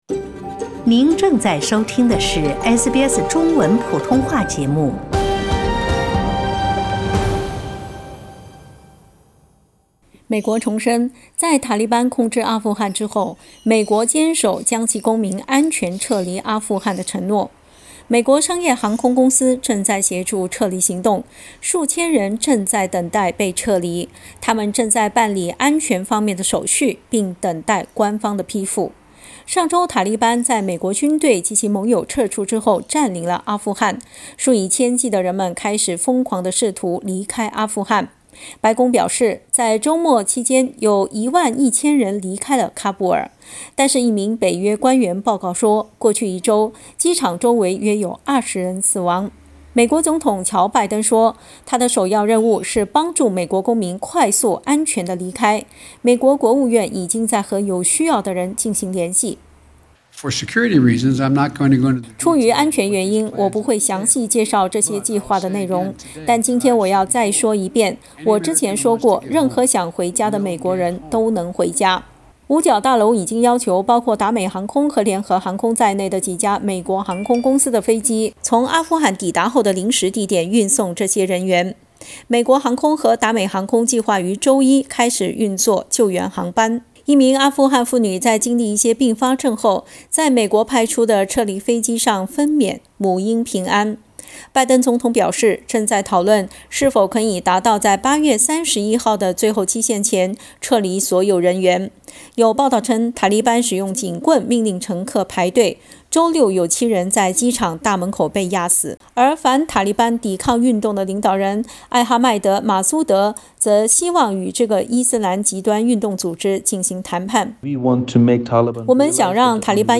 美國重申，在塔利班控制阿富汗之後，美國堅守將其公民安全撤離阿富汗的承諾。（點擊圖片收聽詳細報道）